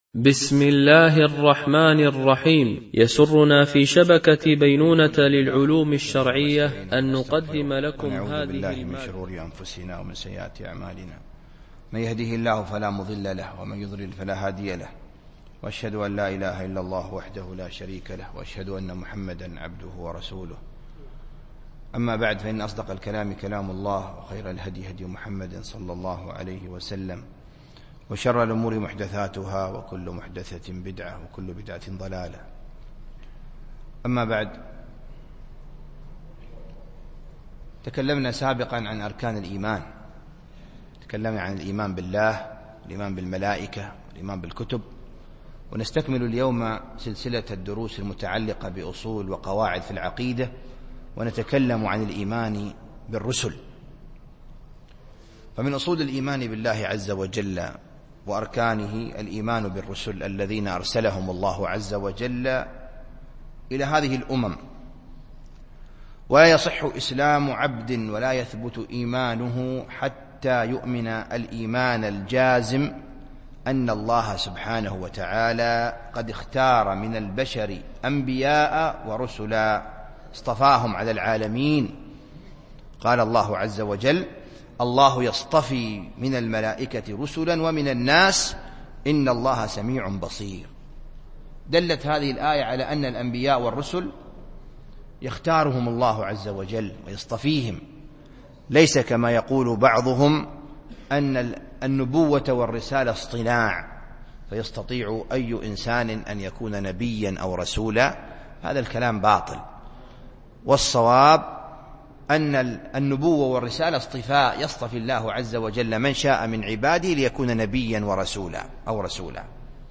سلسلة أصول و قواعد في العقيدة ـ الدرس السادس